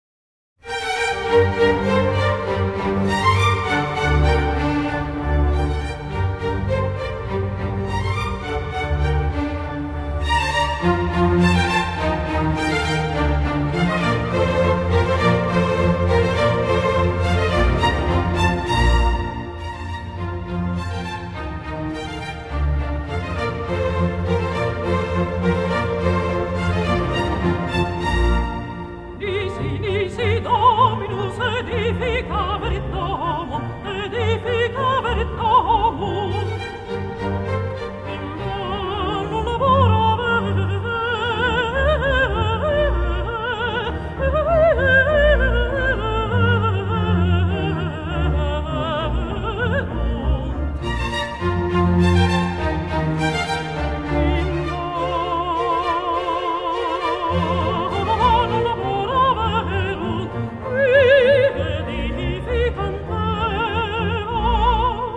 音樂往下走，前奏完，alto聲音出來，現場頓時鴉雀無聲。
宛如詠嘆調般的美麗，卻又帶出為世人祈福的神聖情感。